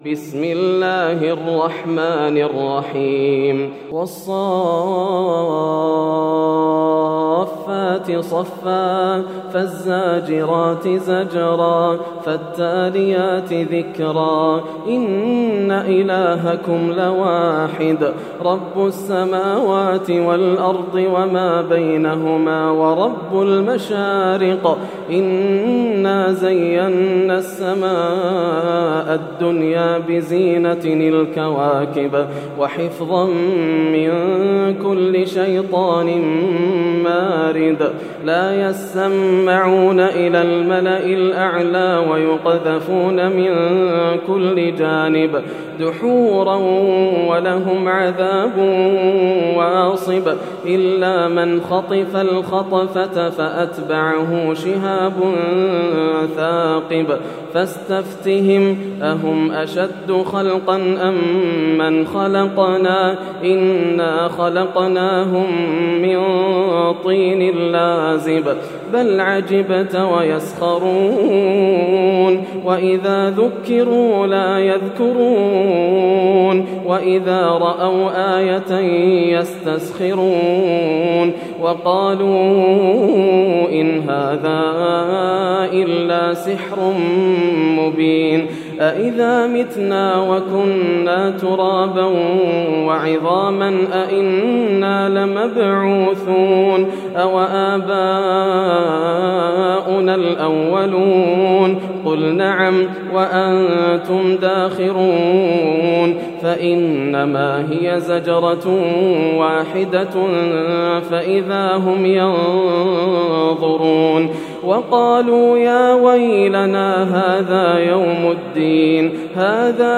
سورة الصافات > السور المكتملة > رمضان 1431هـ > التراويح - تلاوات ياسر الدوسري